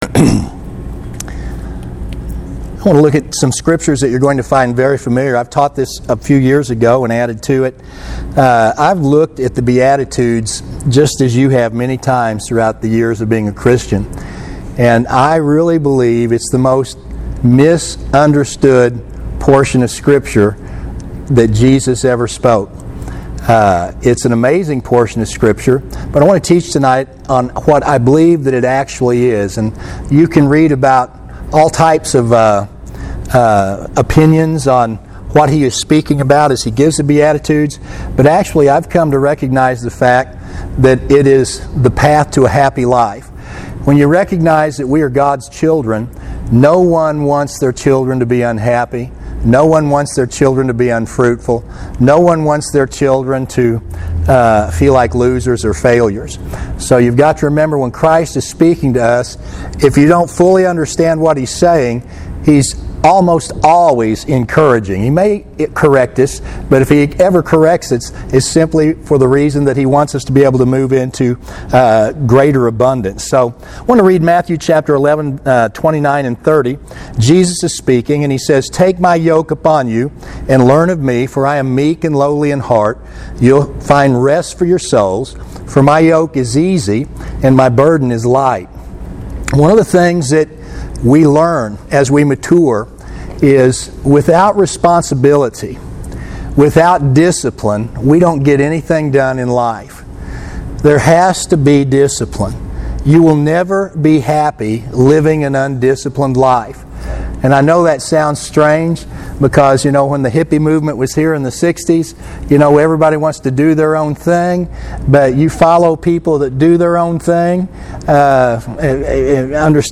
An insightful lesson on a very tough sermon Christ taught. Not tough to hear, but tough to truly understand the meaning Jesus was conveying to those with a different thought on what his words meant 2000 years ago.